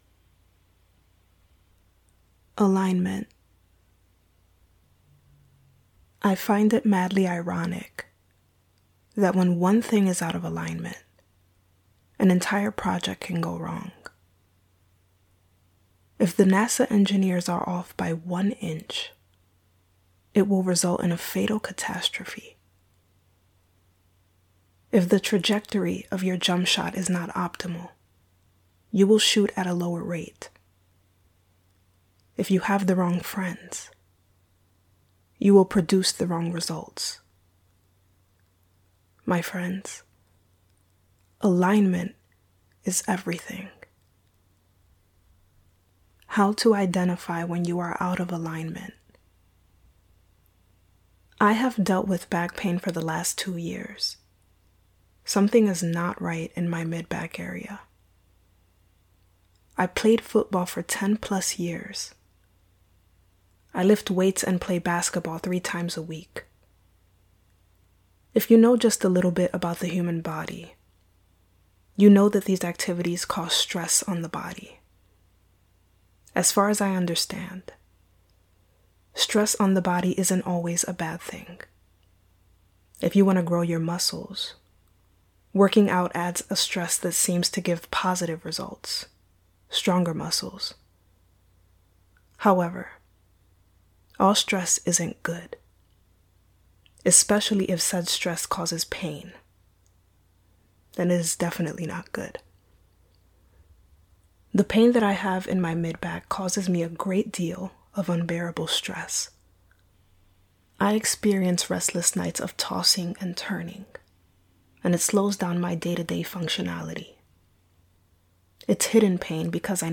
[Sleep-Aid]
poetry reading